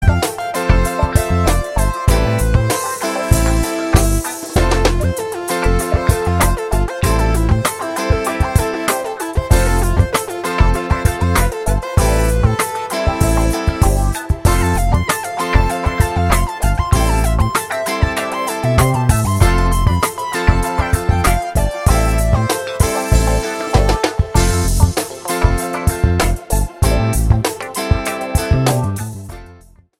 POP  (03.48)